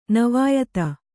♪ navāyata